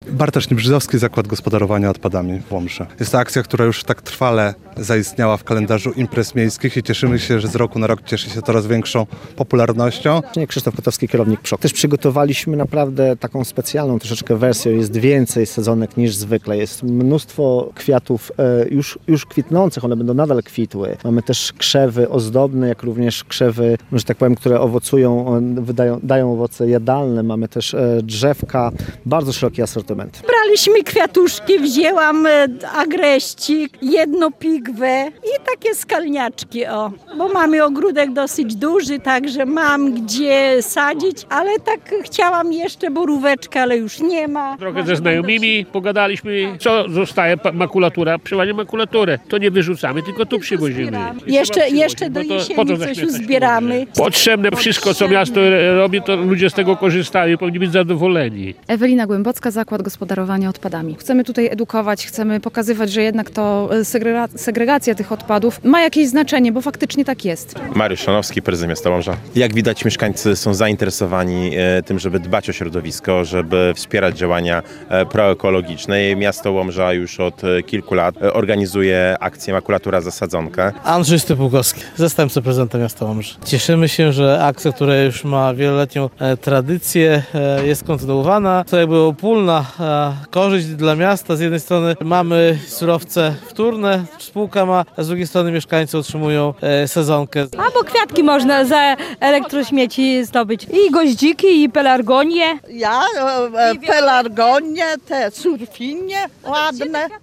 Więcej w naszej relacji: